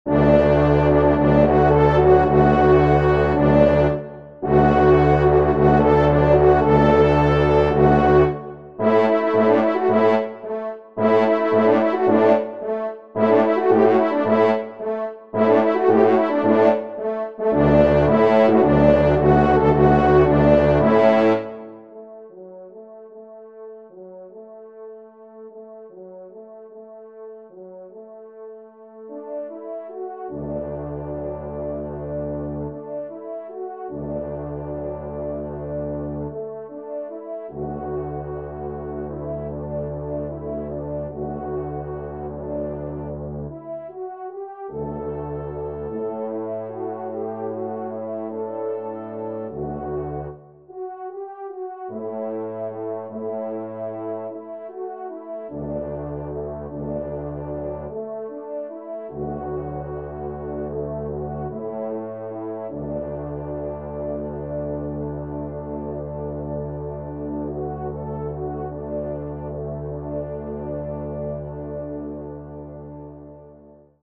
5e Trompe